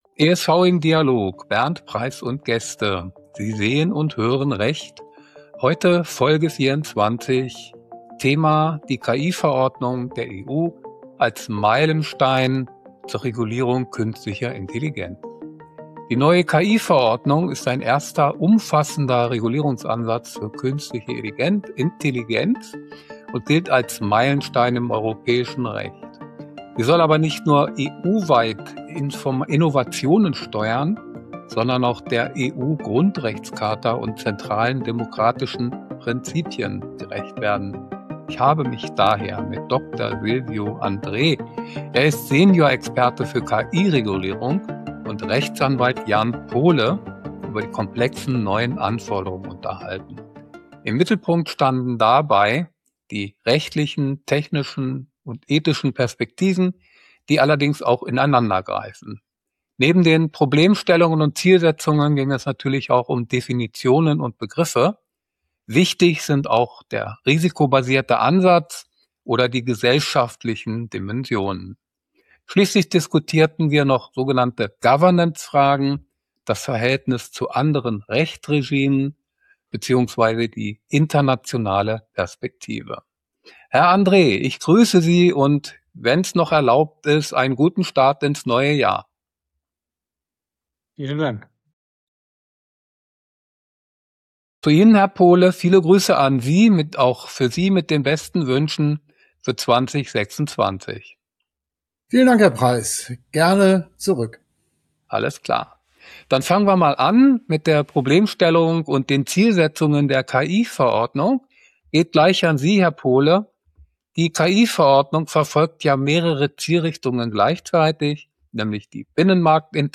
In einem spannenden Interview beleuchten sie die komplexen Anforderungen der Verordnung – und zeigen auf, warum rechtliche, technische und ethische Fragen heute untrennbar miteinander verbunden sind. Die Folge bietet einen kompakten Überblick über die wichtigsten Begriffe und Definitionen und erläutert den risikobasierten Ansatz. Zudem macht sie deutlich, welche gesellschaftlichen Chancen und Herausforderungen die Regulierung mit sich bringt. Zum Abschluss werfen die Gesprächspartner einen Blick auf zentrale GovernanceFragen, das Zusammenspiel mit anderen Rechtsregimen und die internationale Bedeutung der Verordnung.